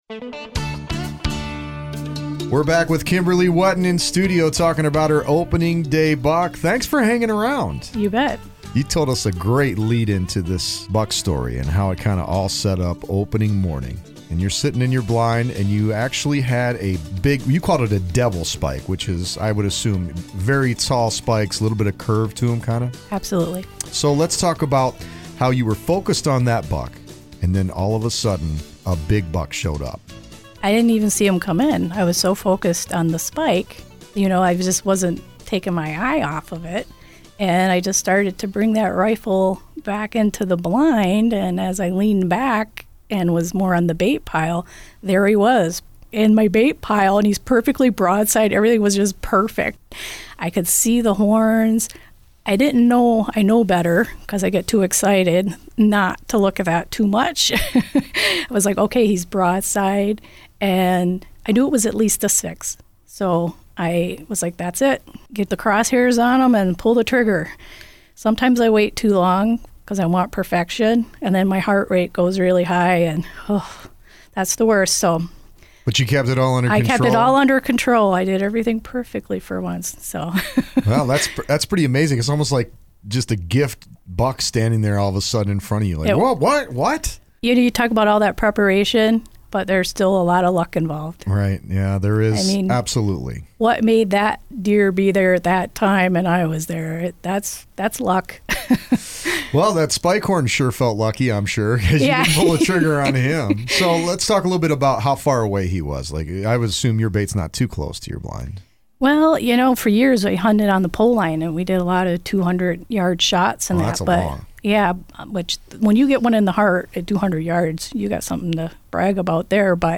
The two guests on the show were both busy over the weekend, and they were happy to share their stories.